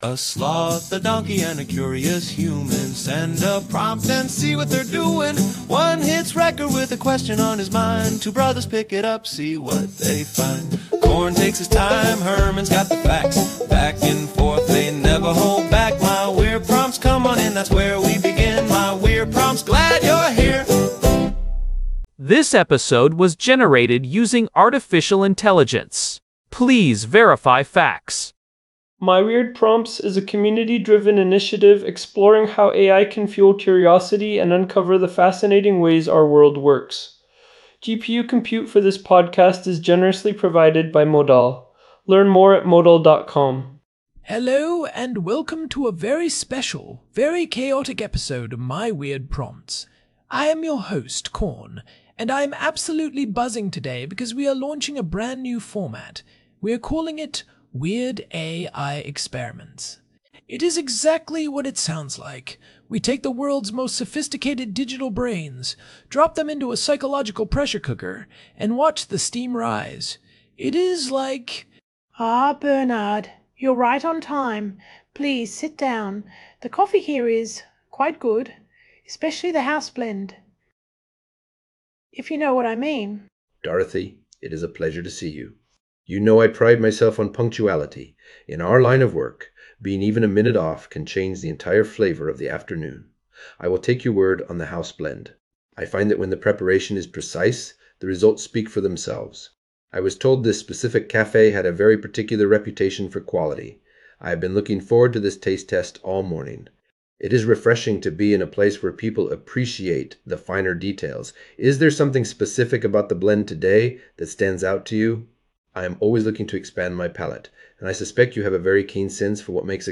AI-Generated Content: This podcast is created using AI personas.
ai-spy-roleplay-experiment.m4a